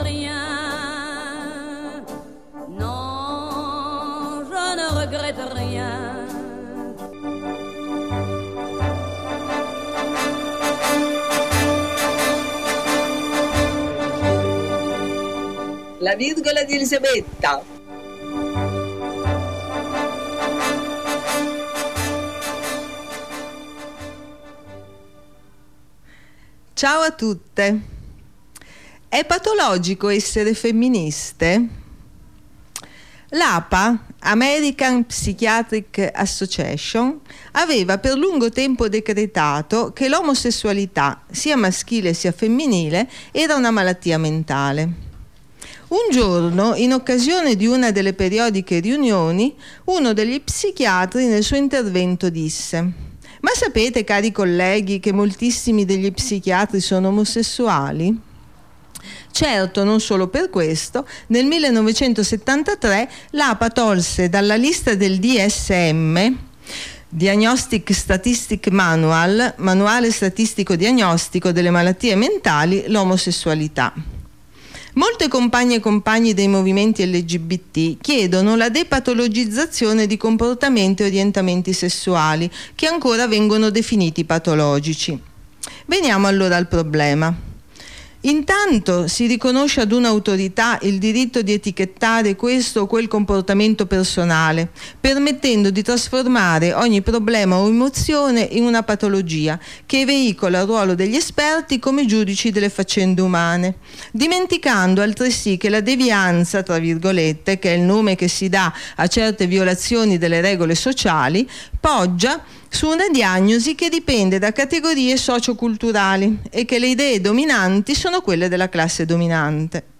Nella giornata dello sciopero delle lavoratrici e dei lavoratori migranti, abbiamo ascoltato le voci delle donne migranti e italiane del Coordinamento Migranti Bologna e Provincia, dell’Associazione Todo Cambia di Milano e dell’Associazione Trama di Terre di Imola, che hanno avviato un percorso di riflessione e scritto un interessante appello.